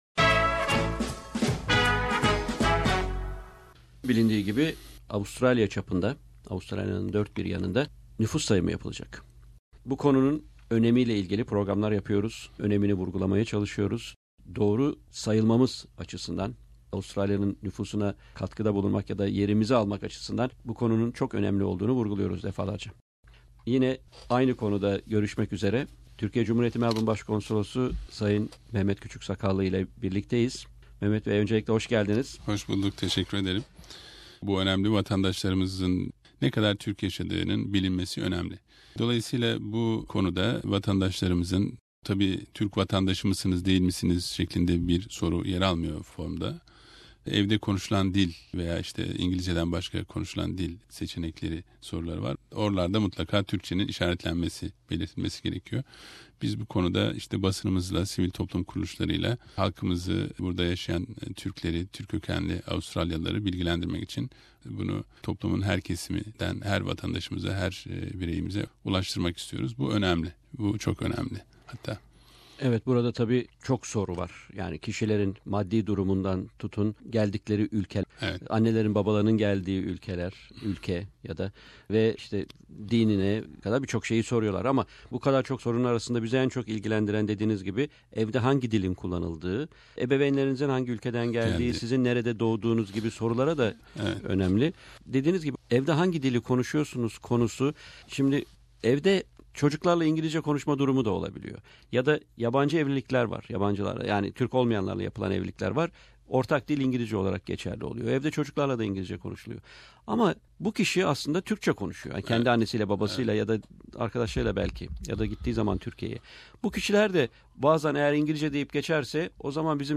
Tonight is the Census night in Australia. SBS Turkish has interviewed the Consul General of Turkey in Melbourne Mr M Küçüksakallı on the importance of the Cencus for the Turkish community of Australia regarding the community's and Turkish speaker's population in this country.